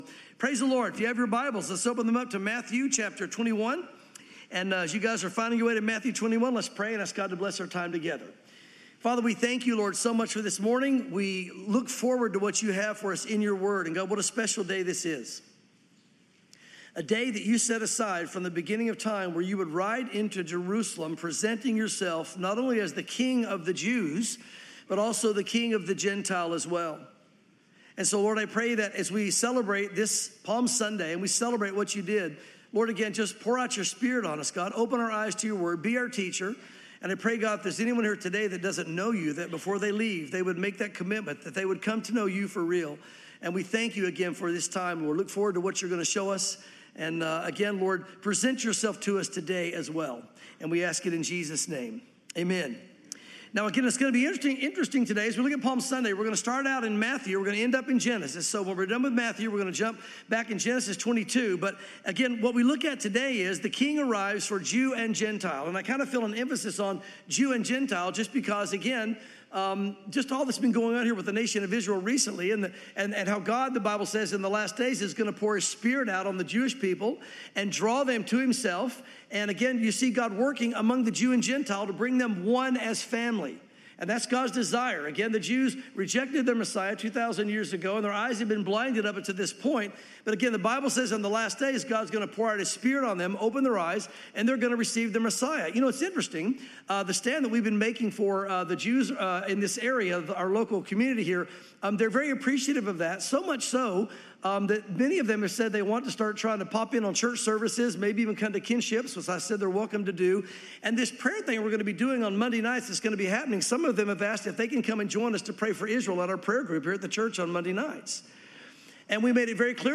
Palm Sunday